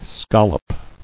[scol·lop]